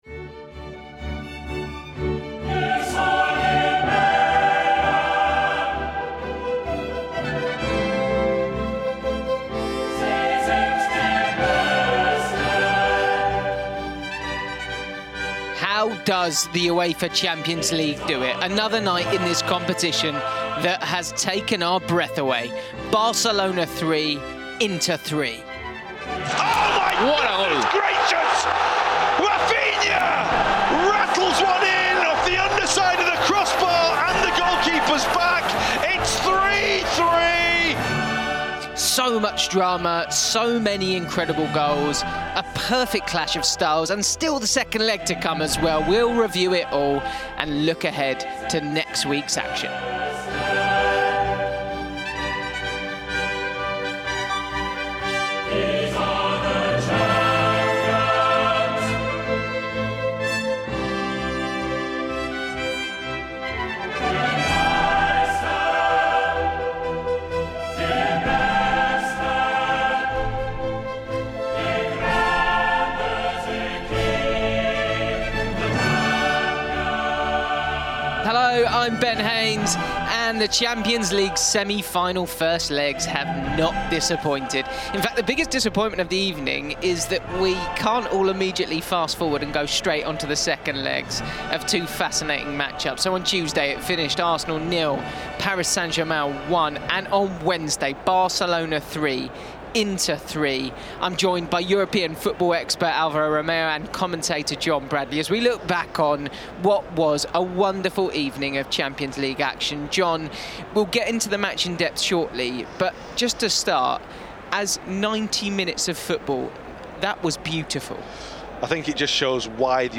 Barcelona and Inter play out an absolute classic in Spain as the second UEFA Champions League semi-final first leg ends 3-3. We analyse the drama, hearing from goal scorer and player of the match, Denzel Dumfries, as well as his Inter teammate, Henrikh Mkhitaryan, and Barcelona boss, Hansi Flick. We also preview next week’s second leg action, including Wednesday’s match in France, where Paris lead Arsenal 1-0. The bosses Luis Enrique and Mikel Arteta give their thoughts ahead of that one.